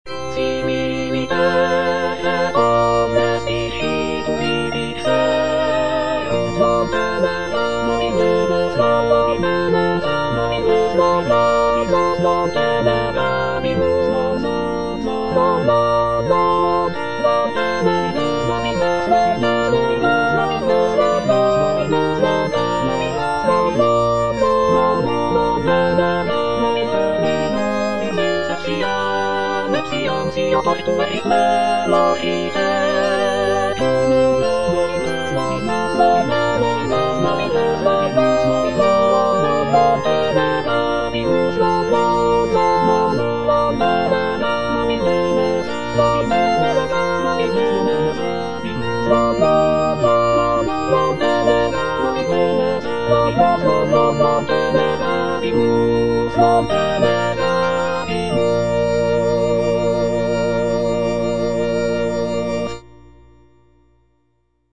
M.A. CHARPENTIER - LE RENIEMENT DE ST. PIERRE Similiter et omnes discipuli dixerunt (soprano I) (Emphasised voice and other voices) Ads stop: auto-stop
It is an oratorio based on the biblical story of Saint Peter's denial of Jesus Christ.